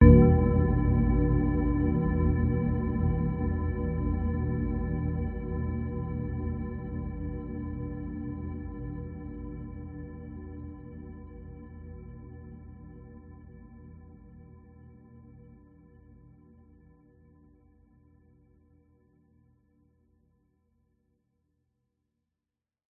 Звук мрачного колокола с жутким эхом